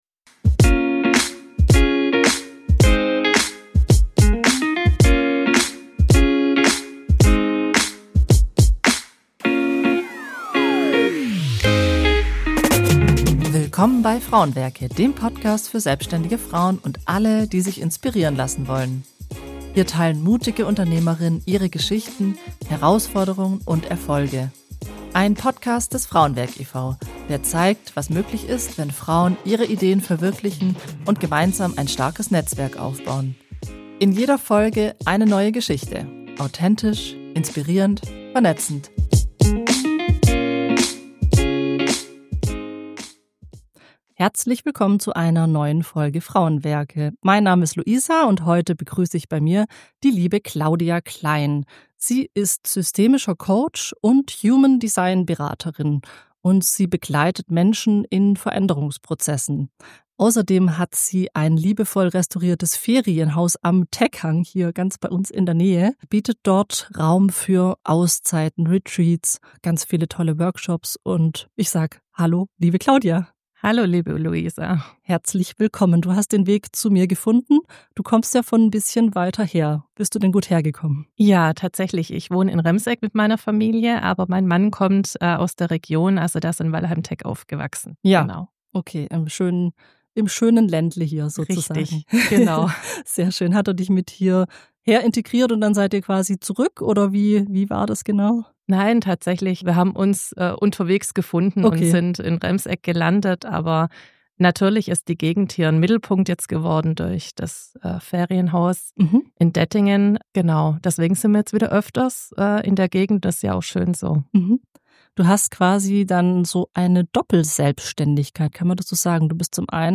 Ein Gespräch über innere Klarheit, Selbstführung, Human Design – und die Kraft eines Ortes, der Veränderung möglich macht.